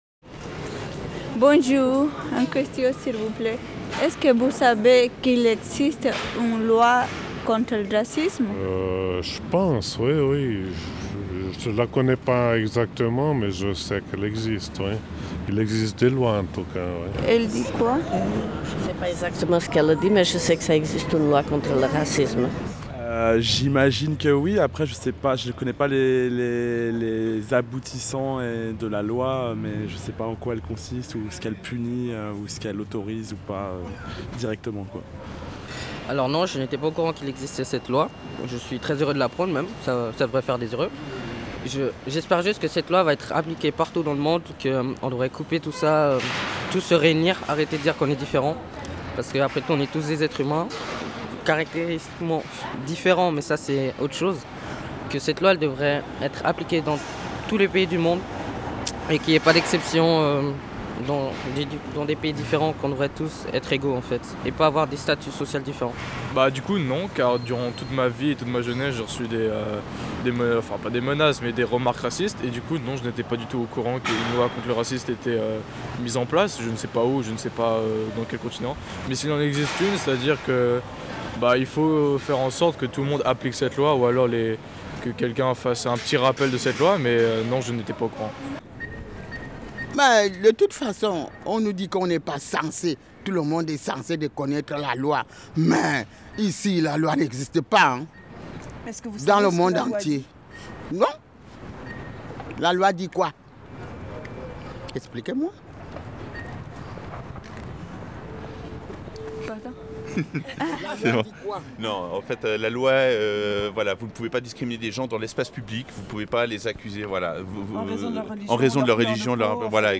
Interviews de la population lausannoise
Voici une première série d’interviews menées le 23 janvier dans le quartier lausannois du Flon à Lausanne.